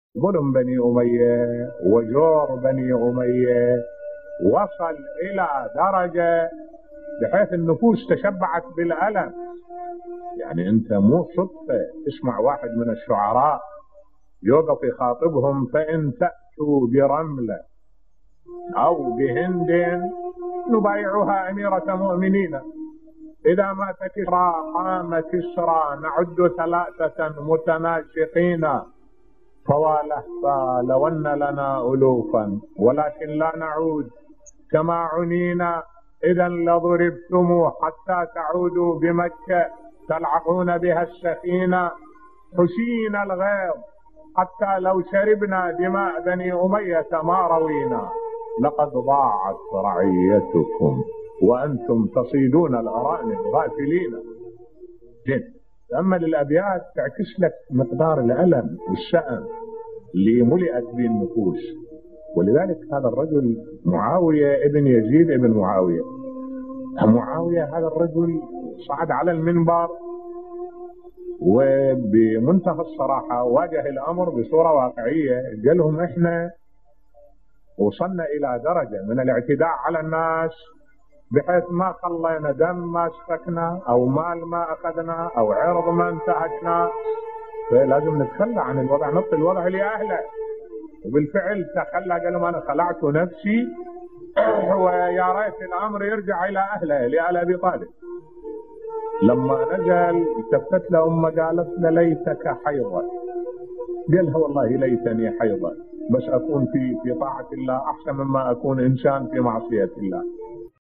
ملف صوتی ظلم الدولة الأموية بصوت الشيخ الدكتور أحمد الوائلي